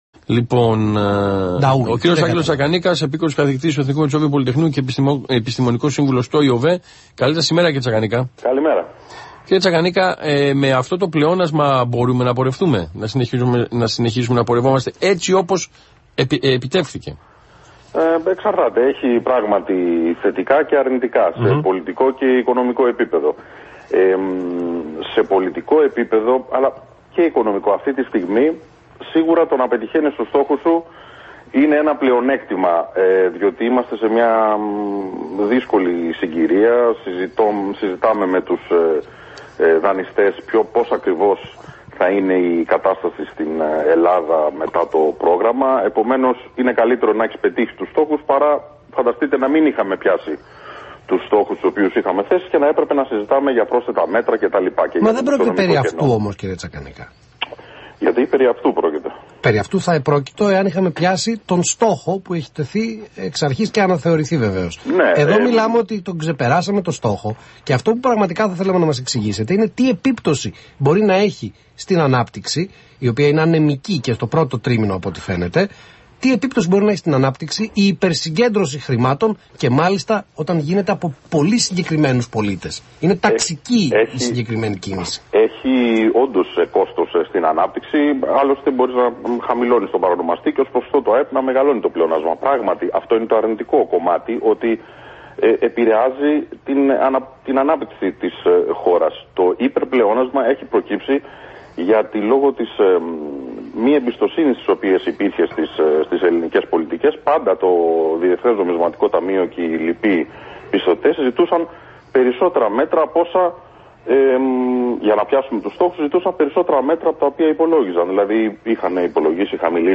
Συνέντευξη στον Αθήνα 9,84